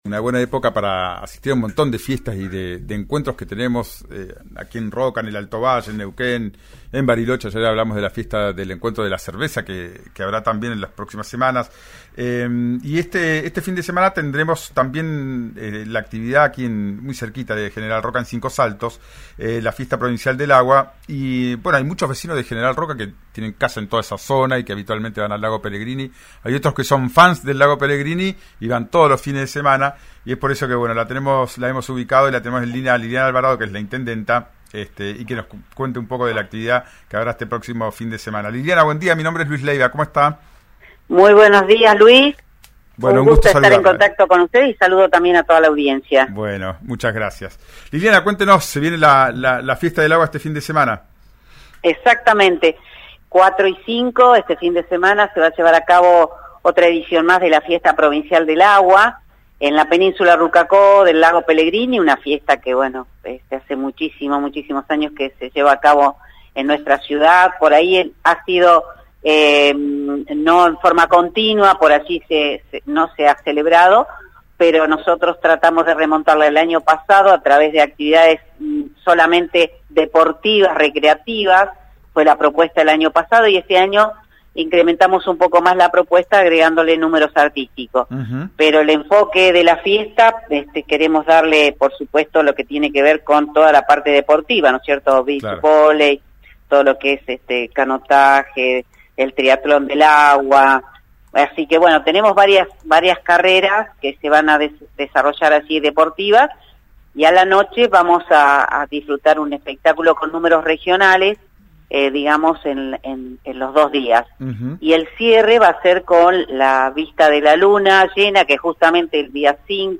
Escuchá a Liliana Alvarado, intendenta de Cinco Saltos, en «Ya es tiempo», por RÍO NEGRO RADIO.